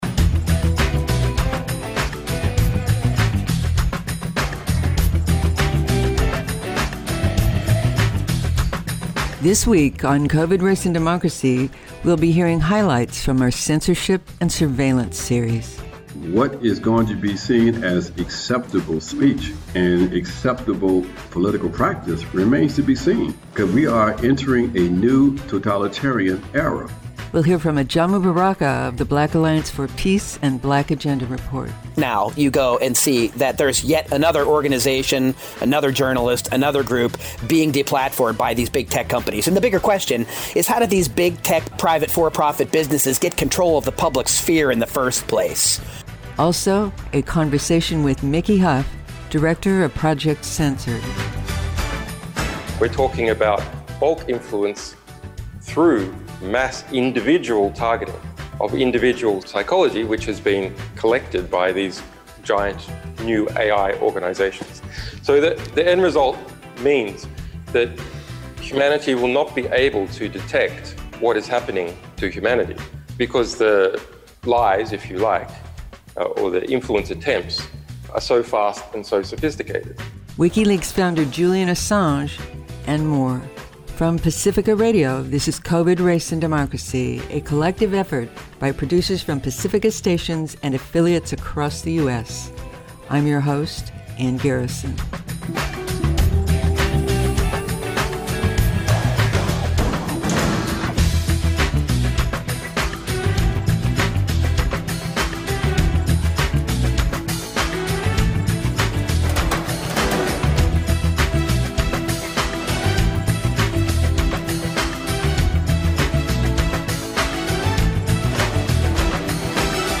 *** In December 2017, imprisoned whistleblower Julian Assange addressed the Holberg Debate in Oslo, Norway via video link from inside the Ecuadorian Embassy where he took refuge between 2012 and 2019.